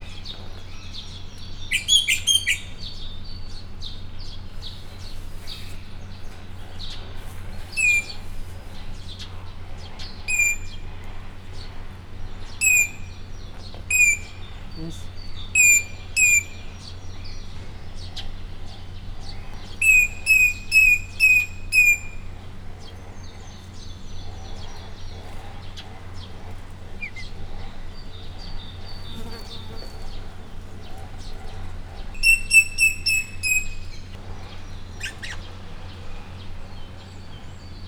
Directory Listing of /_MP3/allathangok/miskolcizoo2018_professzionalis/rozellapapagaj/